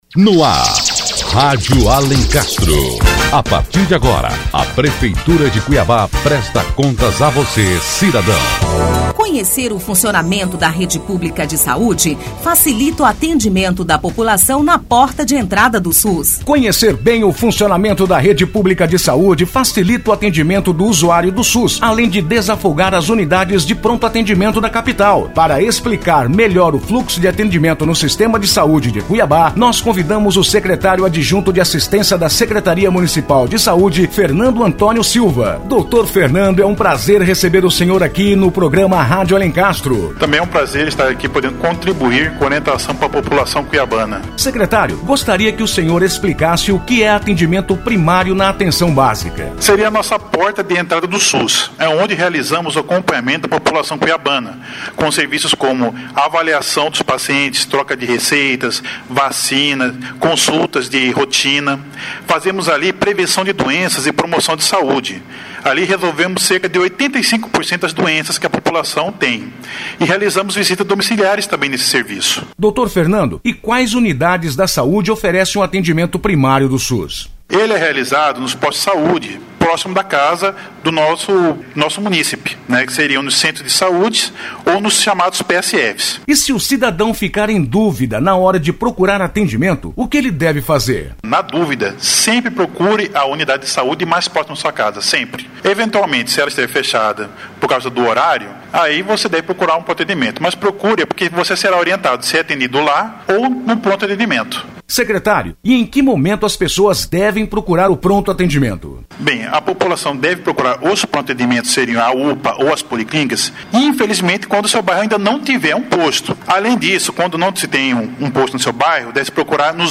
Notícias / 60º Programa 20 de Fevereiro de 2014 17h58 Conheça como funciona o atendimento na saúde O Secretário Adjunto de Assistência da Secretaria Municipal de Saúde, Doutor Fernando Antônio Silva, explica como funciona o fluxo de atendimento no sistema de saúde da nossa capital.